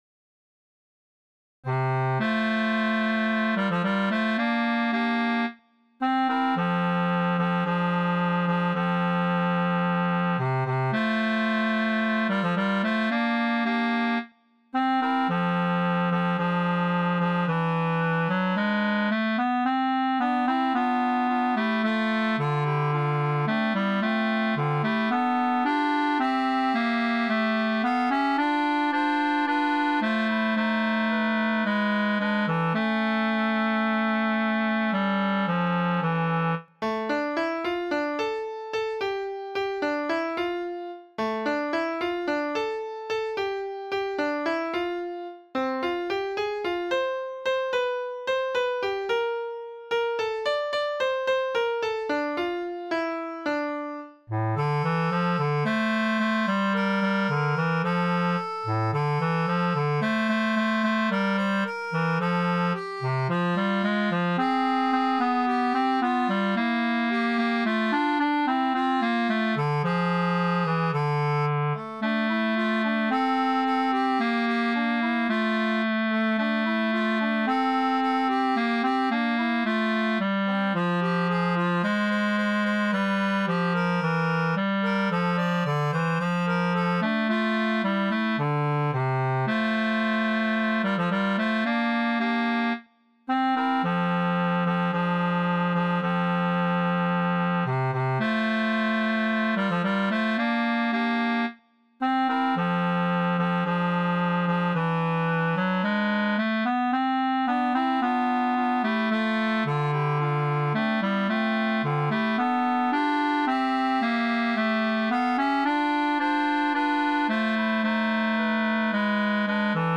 siman_she_od_lo_tenor_bass.mp3